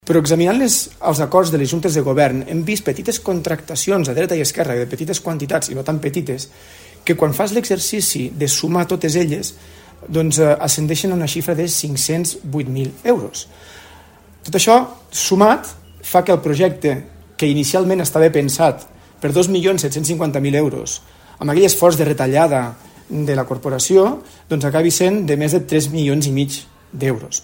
El conseller de la minoria, David Astrié, ha denunciat que el projecte, adjudicat inicialment per 2,75 milions d’euros, acabarà superant els 3,5 milions a causa de desviacions i contractacions complementàries.